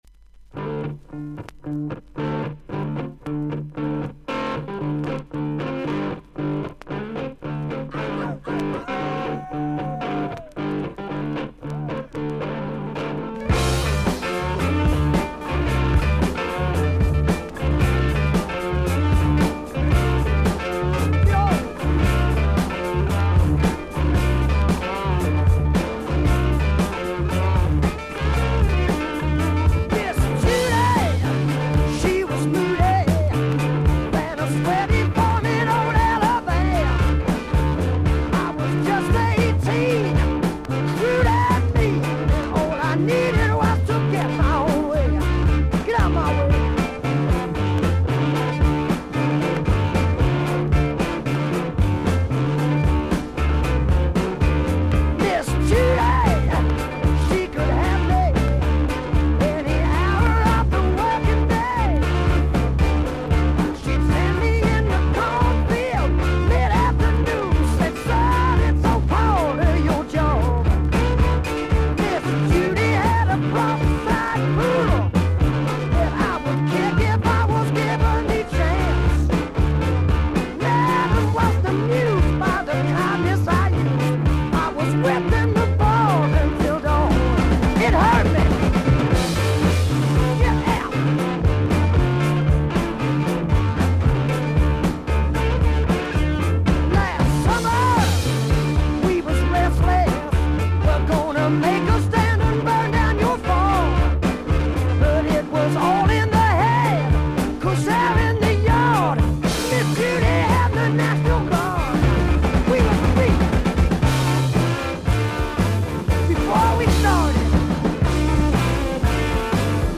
A1最初に5mmのキズ、少々軽い周回ノイズあり。
少々サーフィス・ノイズあり。クリアな音です。